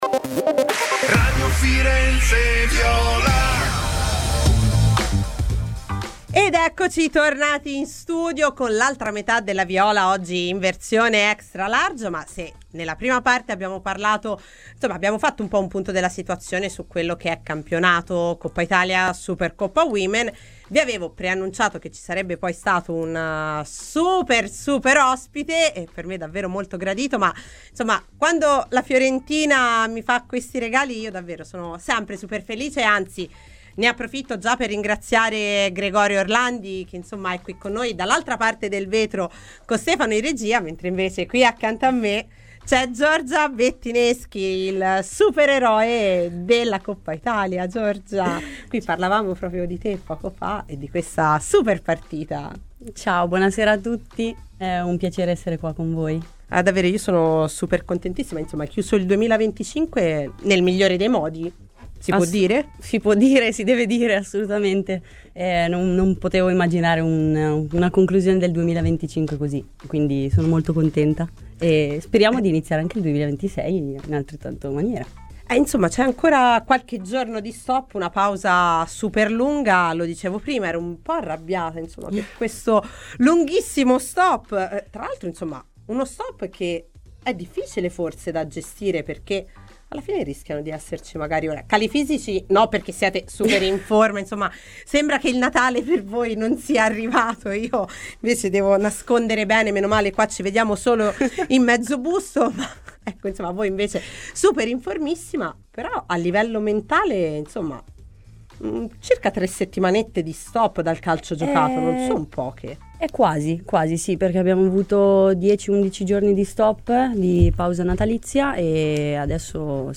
Clicca qui per il podcast con l'intervento integrale.